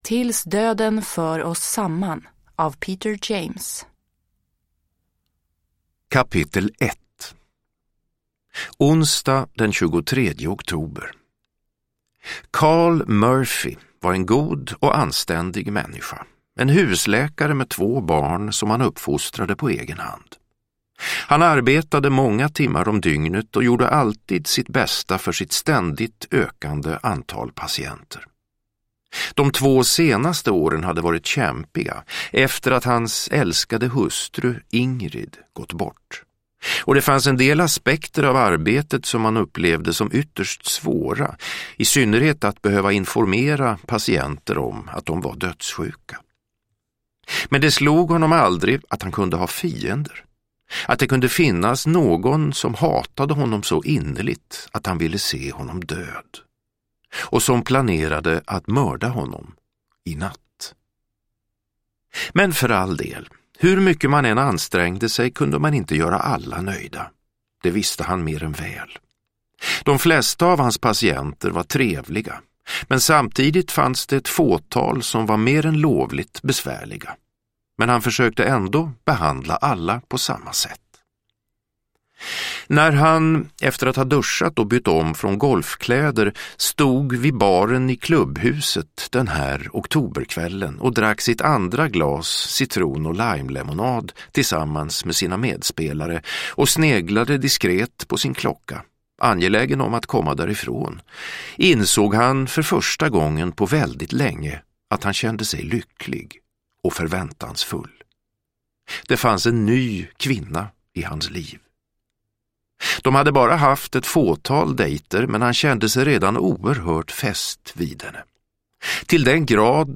Uppläsare: Peder Falk